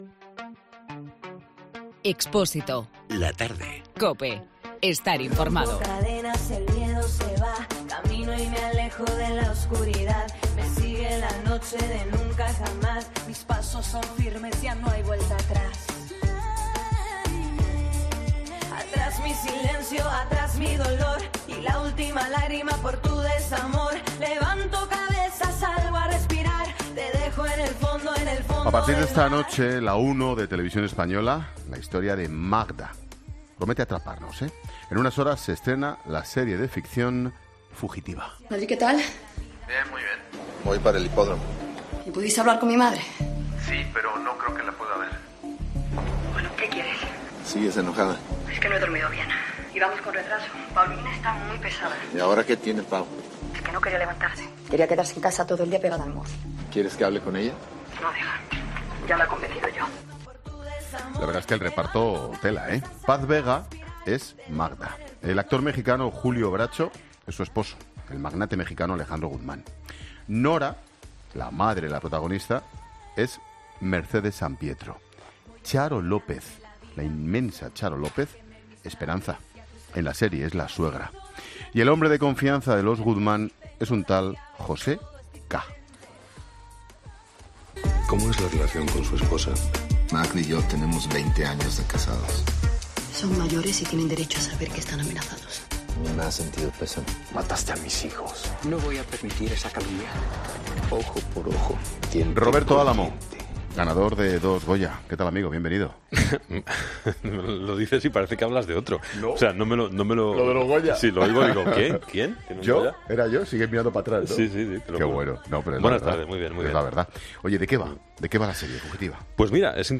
Ángel Expósito entrevista a Roberto Álamo horas antes de que se estrene la nueva serie de TVE, "Fugitiva"